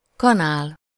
Ääntäminen
Ääntäminen Tuntematon aksentti: IPA: /kɥi.jɛʁ/ Haettu sana löytyi näillä lähdekielillä: ranska Käännös Ääninäyte Substantiivit 1. kanál Suku: f .